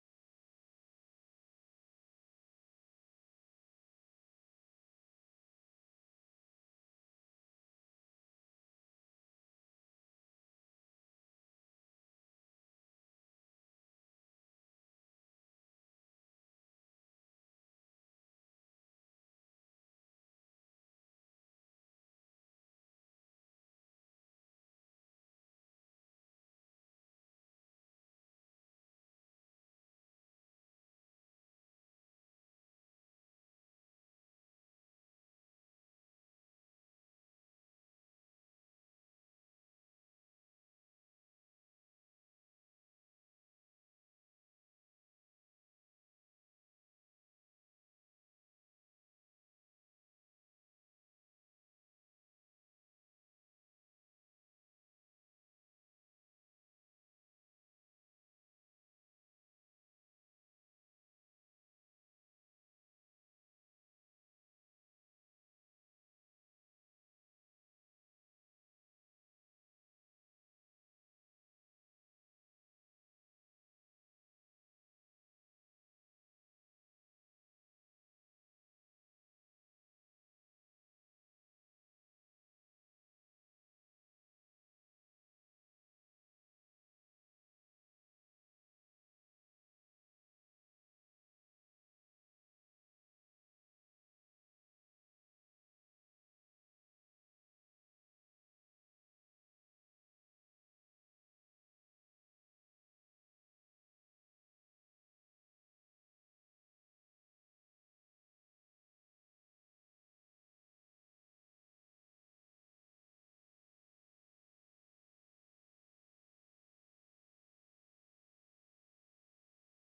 Spring Revival 2025 Passage: Luke 2 Service Type: Revival « Spring Revival 2025